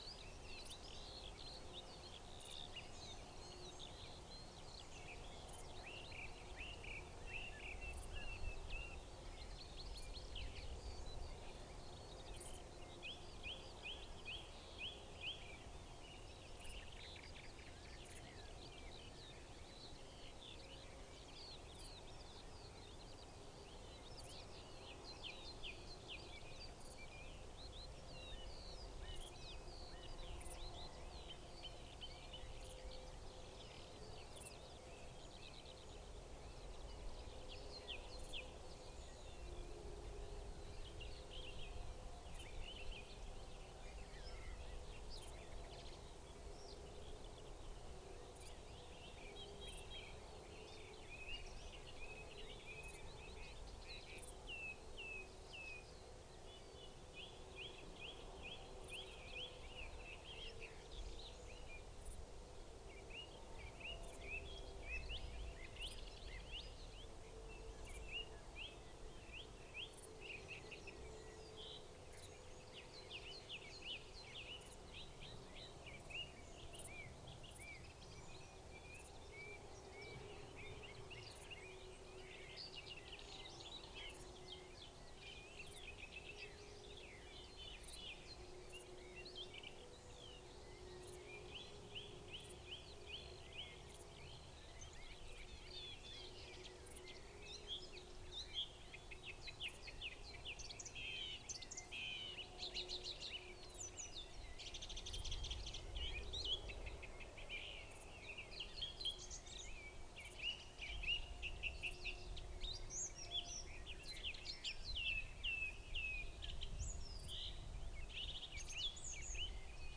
Sylvia communis
Alauda arvensis
Turdus merula
Emberiza citrinella
Coturnix coturnix